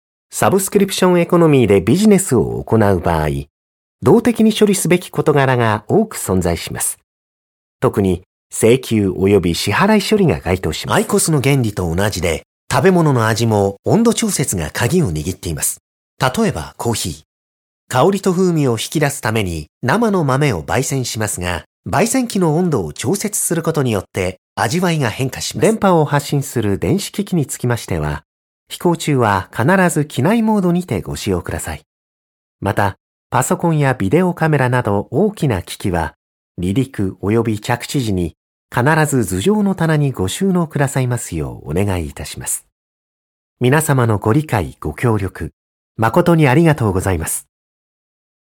JP DT EL 01 eLearning/Training Male Japanese